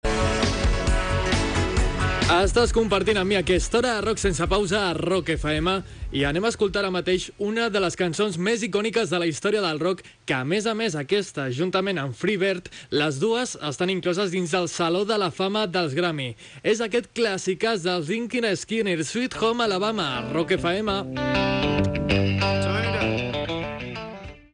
Identificació del programa i de l'emissora, tema musical
Musical